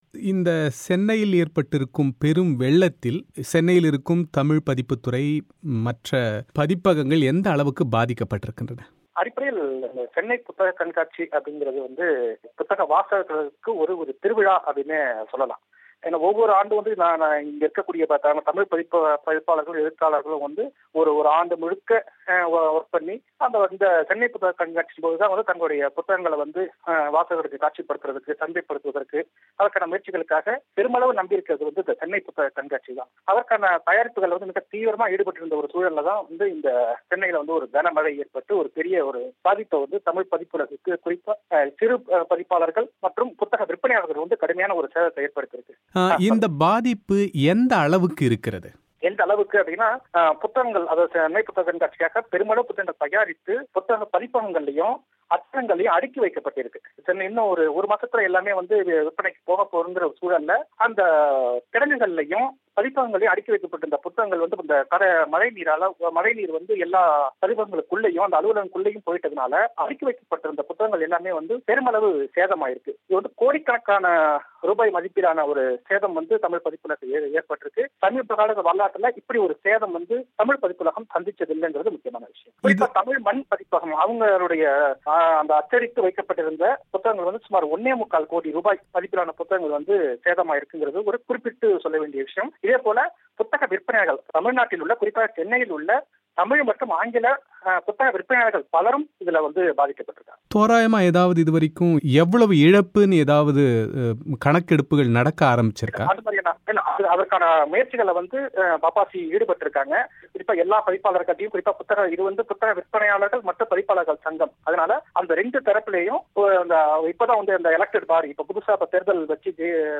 பிபிசி தமிழோசைக்கு அளித்த பிரத்யேக செவ்வி.